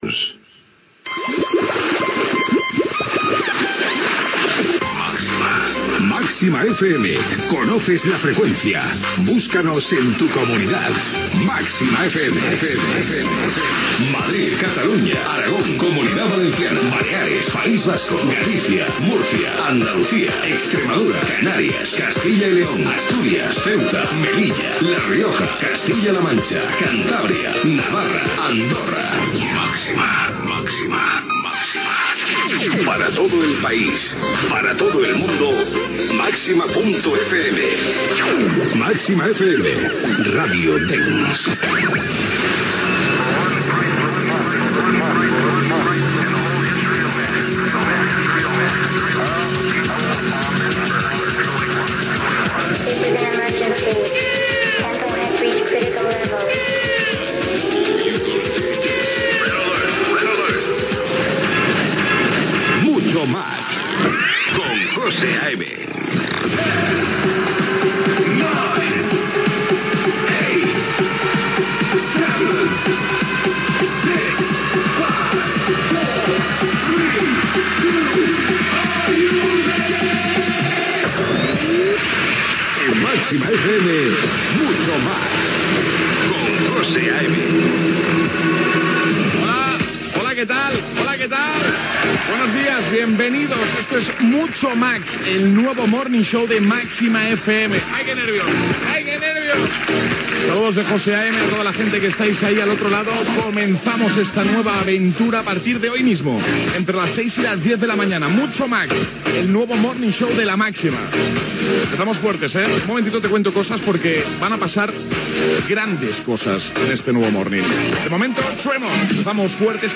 Indicatiu de la ràdio, careta del programa, salutació i presentació del primer programa, tema musical, indicatiu
Musical